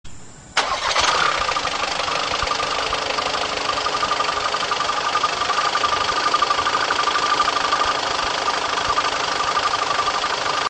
セルモータの音、AQUA DREAMに交換後
START/STOPシステム装備のディーゼル車なので、クランキング時間はみじかく、
もともとすぐに始動するのだが、僅かに早めに始動する程度でその大きな差は無い。